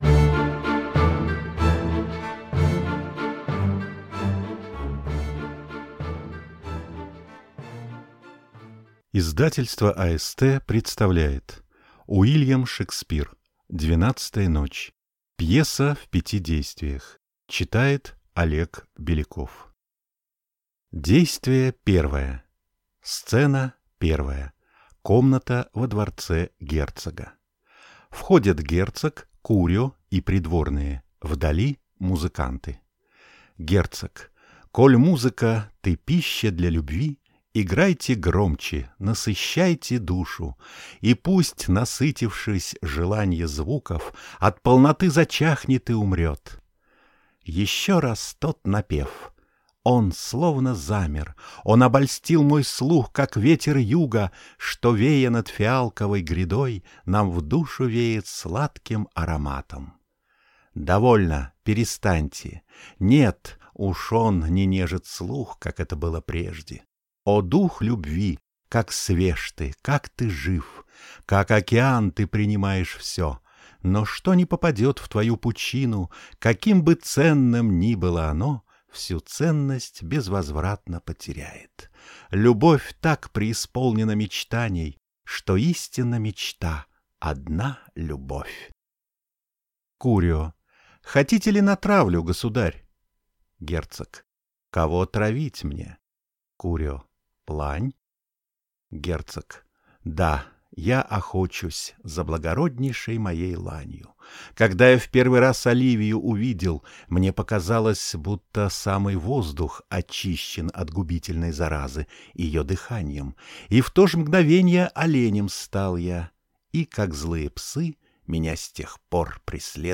Аудиокнига Двенадцатая ночь | Библиотека аудиокниг